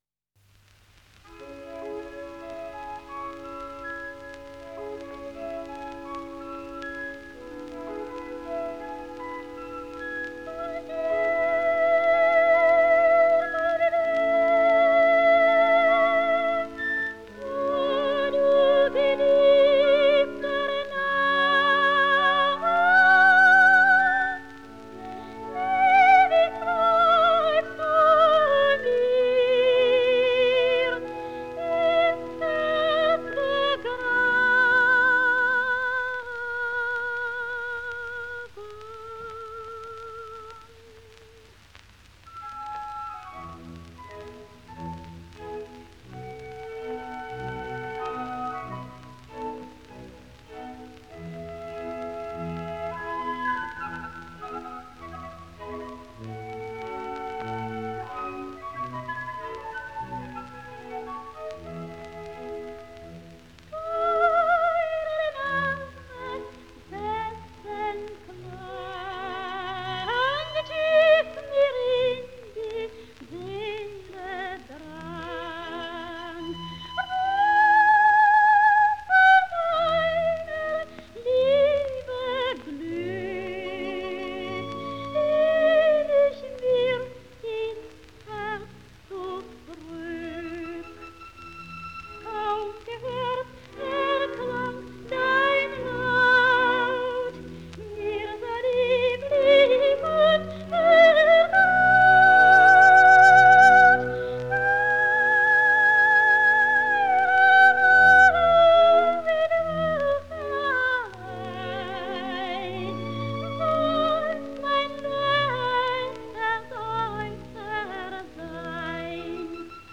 Miliza Elizabeth Korjus; 18 августа 1909 — 26 августа 1980) — оперная певица (колоратурное сопрано) и актриса литовско-эстонского происхождения, исполнительница роли Карлы Доннер в музыкальном фильме «Большой вальс» (1938).